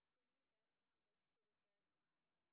sp27_street_snr0.wav